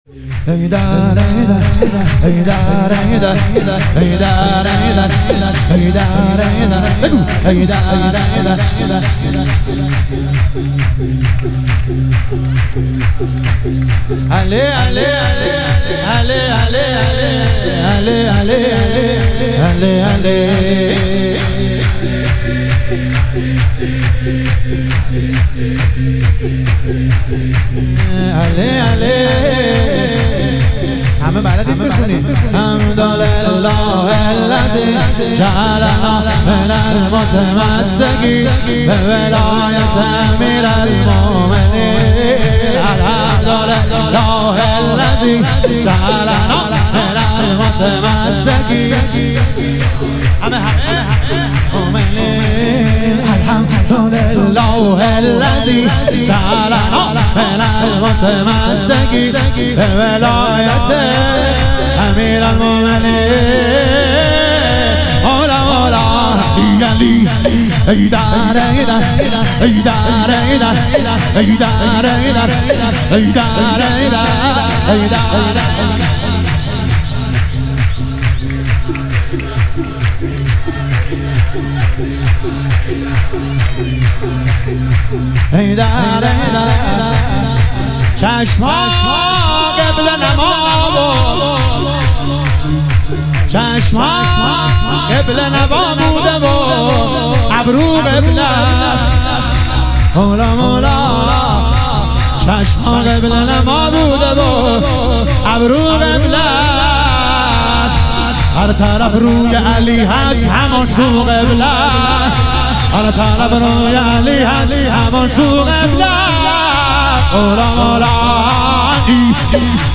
ظهور وجود مقدس حضرت علی اکبر علیه السلام - شور